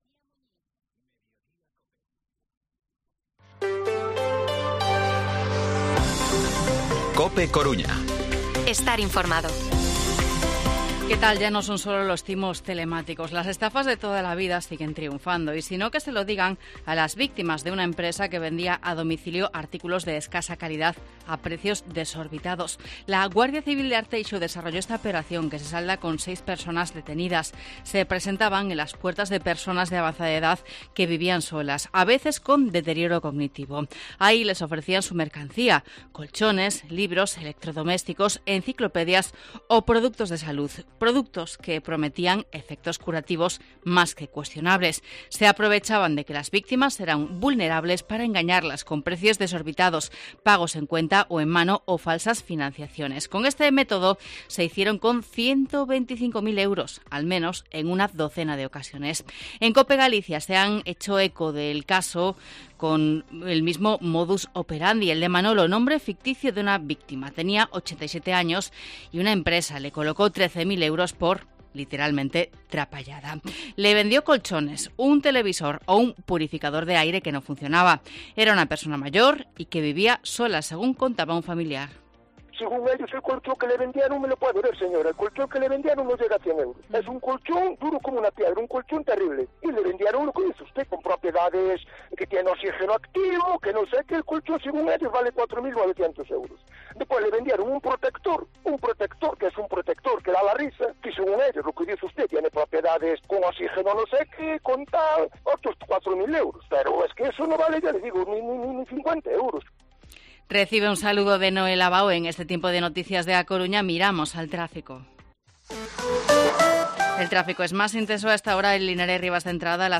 Informativo Mediodía COPE Coruña martes, 6 de junio de 2023 14:20-14:30